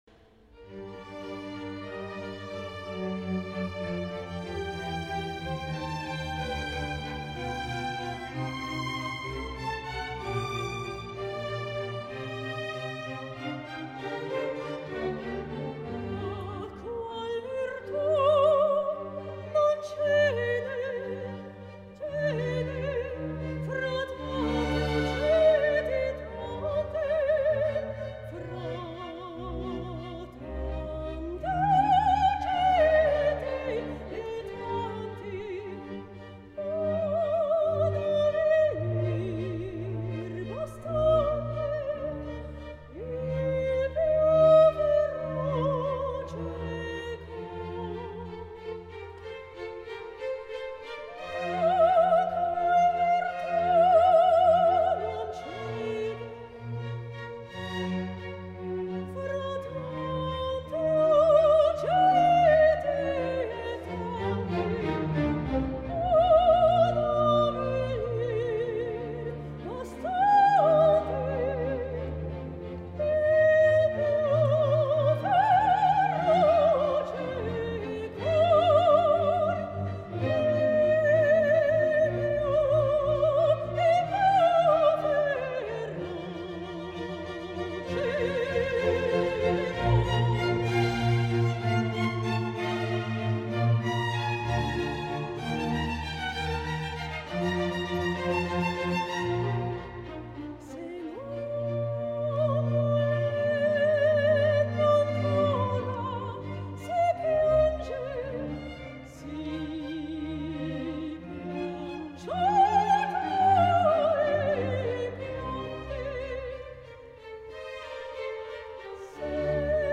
Auszüge aus Konzert Aufnahmen:
W.A.Mozart, „La Betulia Liberata“, Azione sacra KV118 | Konzertmitschnitte aus dem Jahr 2006